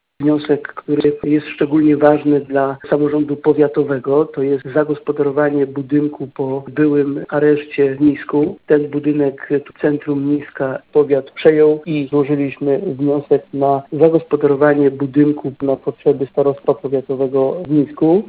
Mówi Starosta niżański Robert Bednarz